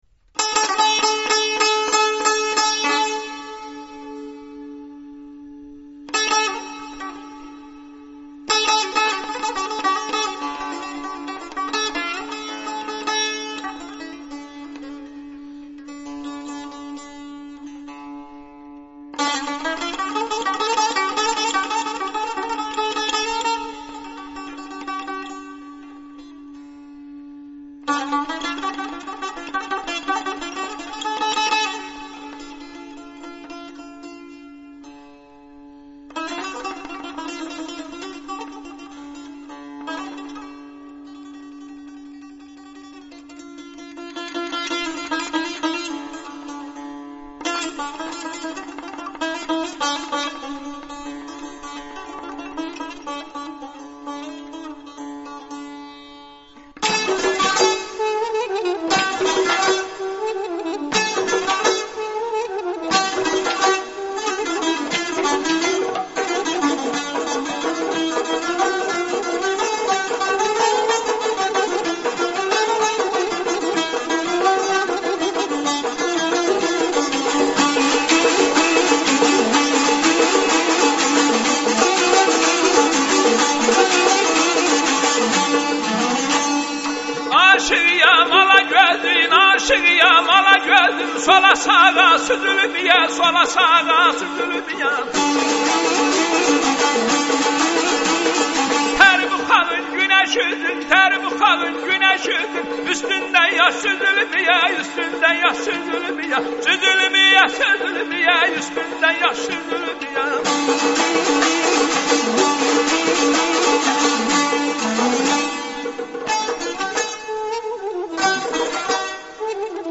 گونئی آزربایجان موزیك لری